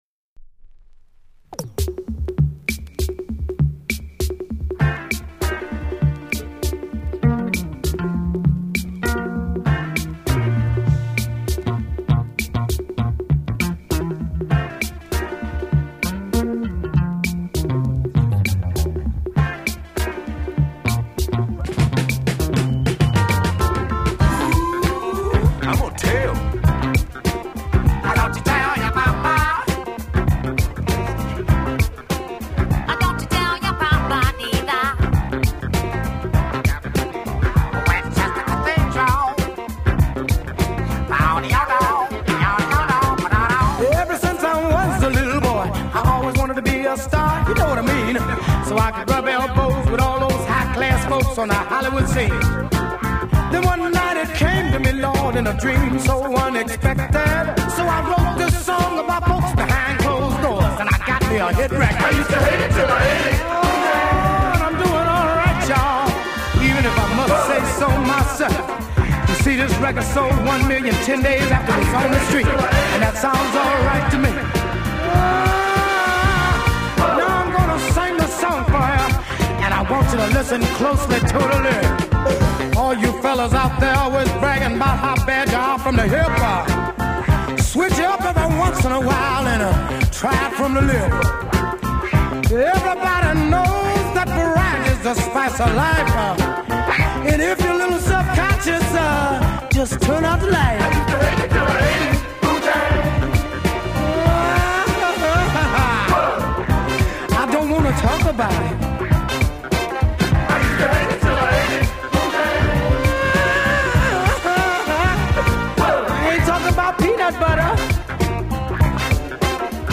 Hard and virtuos rhythm, giving a heavy weight impression.